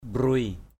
/ɓruɪ/ mbruei O&] [Ram.] 1. (đg.) rải nước. mbruei aia di angah apuei O&] a`% d} aZH ap&] rải nước lên than lửa. tuh aia mbruei di njam t~H a`% O&]...